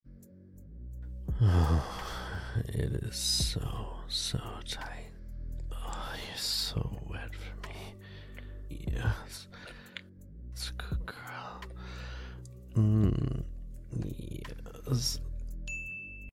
🥴👀Close your eyes,put on headphones and feel deep voice next to you...🥵